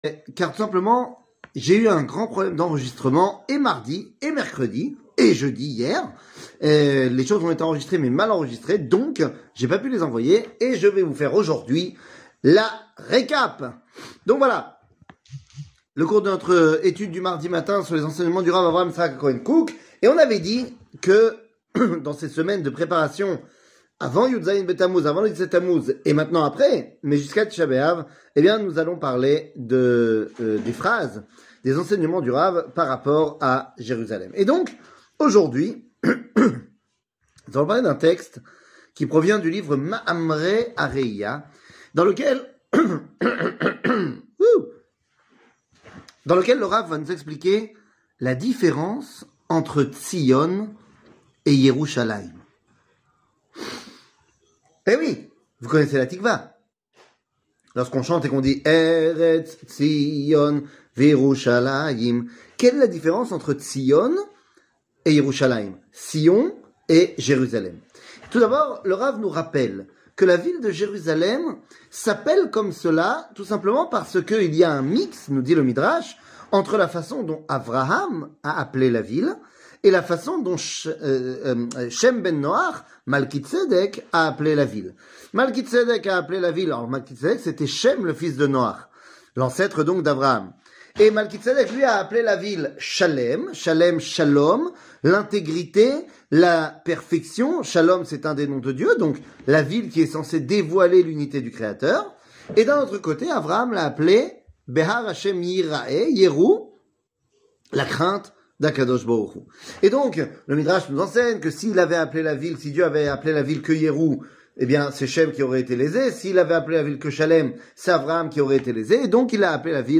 Rav Kook, Jerusalem 2, Sion et Jerusalem 00:05:15 Rav Kook, Jerusalem 2, Sion et Jerusalem שיעור מ 07 יולי 2023 05MIN הורדה בקובץ אודיו MP3 (4.79 Mo) הורדה בקובץ וידאו MP4 (8.3 Mo) TAGS : שיעורים קצרים